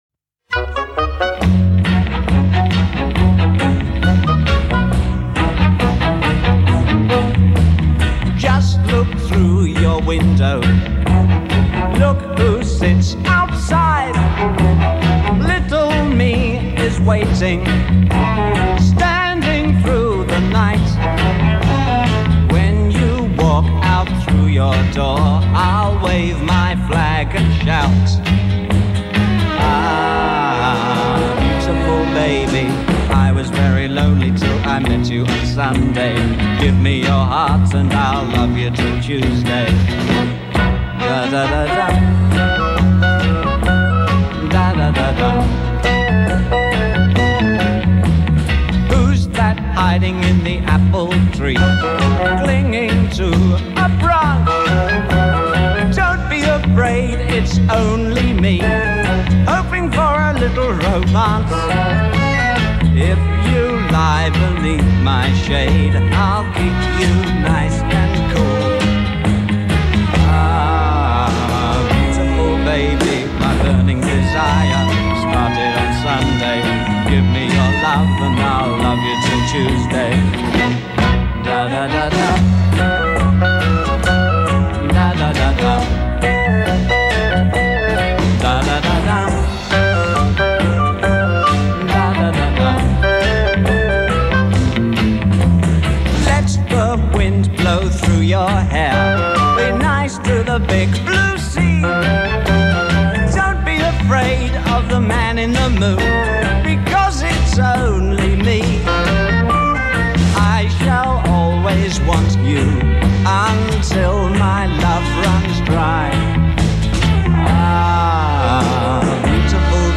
a solo artist, a singer-songwriter
a 16 piece orchestra with lush arrangements